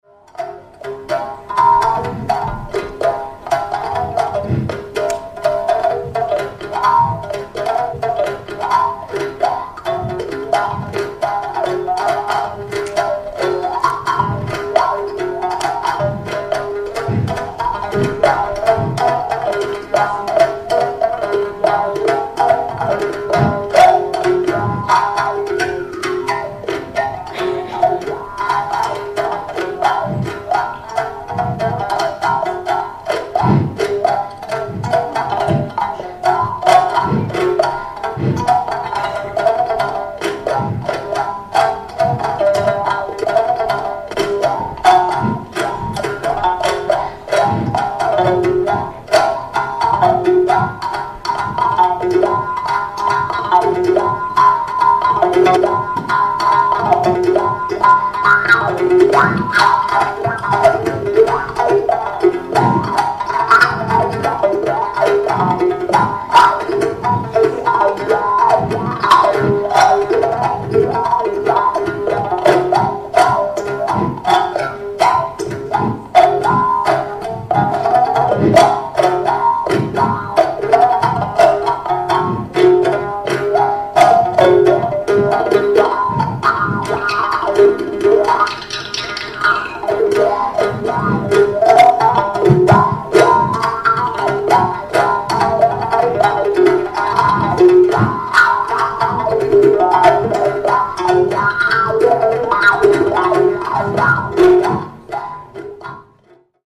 Коллекция инструментов Кафедры Звуковой Микрохирургии пополнилась, в связи с чем на странице "инструменты" обновление - появилось несколько новых видео и аудиофрагментов: Balafon, Frame Drum, Plastic Balloon, Teapot With Water, Dan Moi,